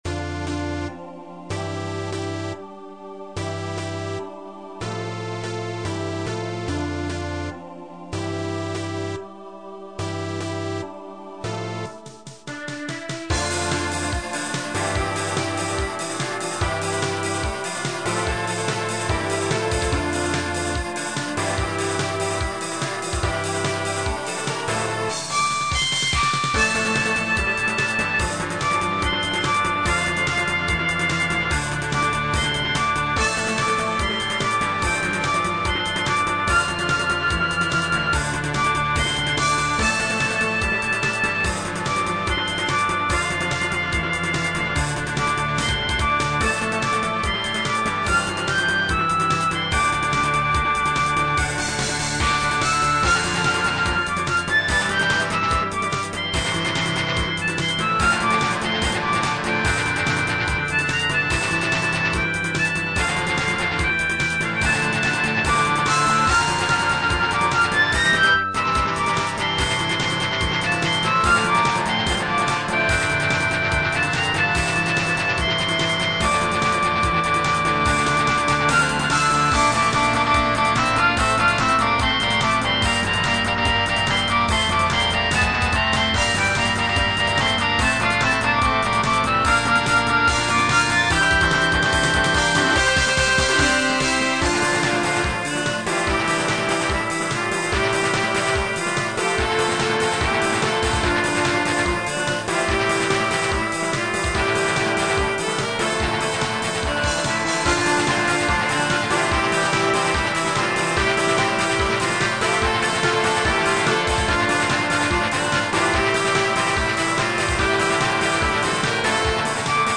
BPM=145
ロボット起動時のBGMとかにどうでしょう。